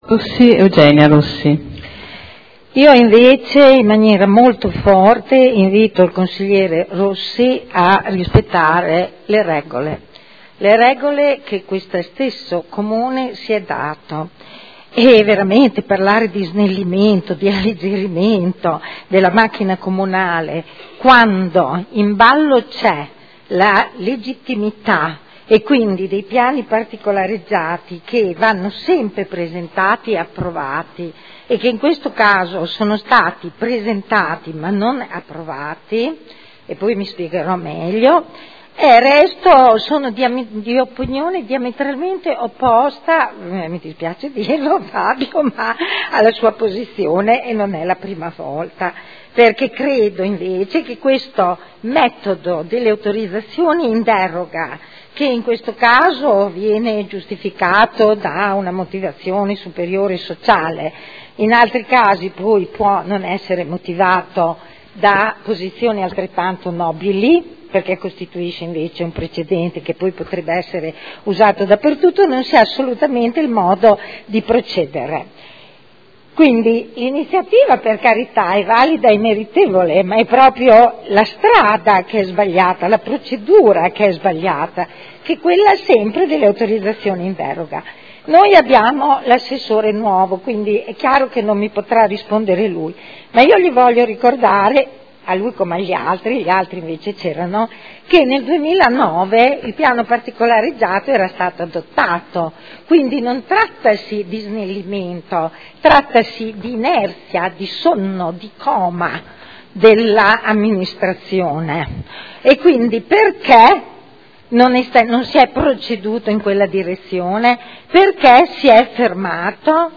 Eugenia Rossi — Sito Audio Consiglio Comunale
Dibattito su proposta di deliberazione.